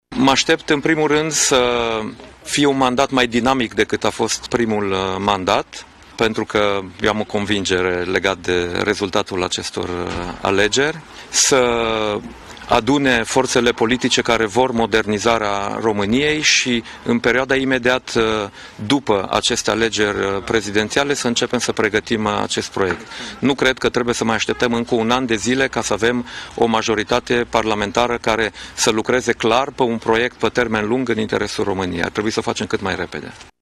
Europarlamentarul Dacian Cioloș, președinte al formațiunii PLUS a votat la o secție deschisă în orașul Alexandria. El și-a exprimat opțiunea de vot în jurul orei 11,00: